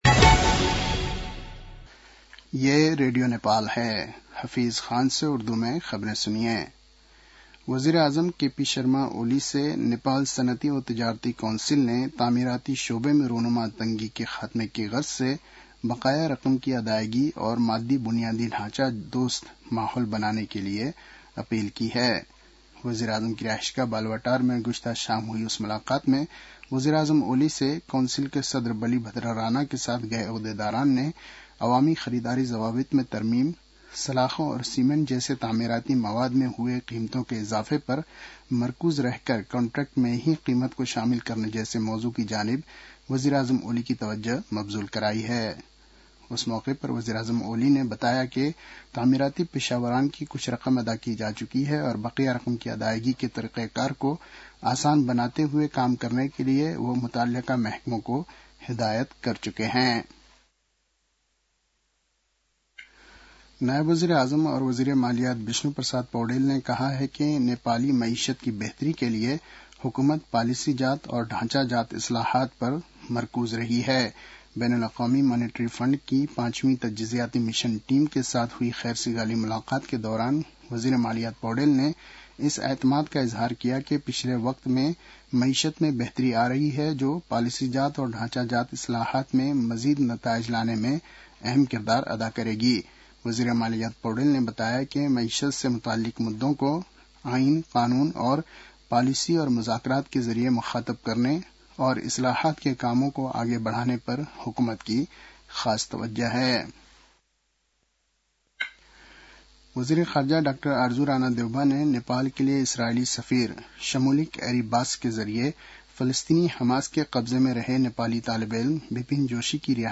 उर्दु भाषामा समाचार : २५ पुष , २०८१
Urdu-news-9-24.mp3